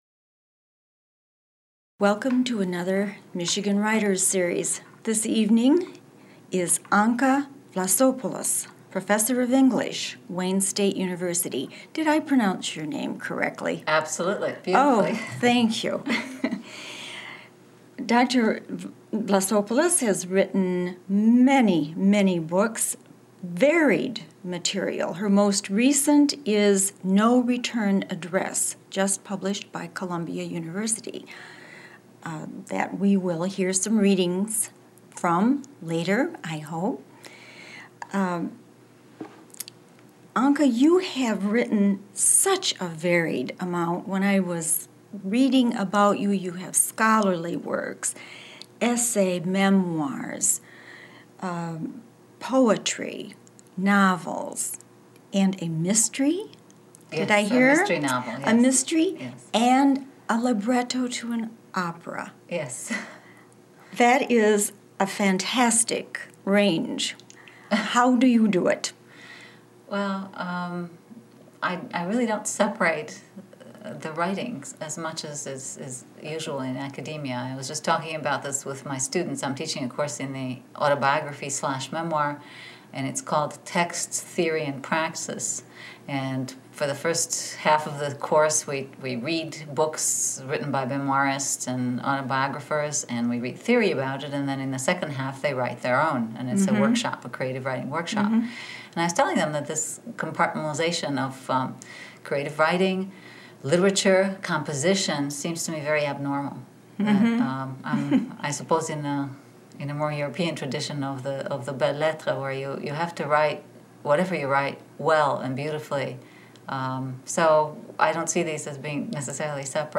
interviews poet and novelist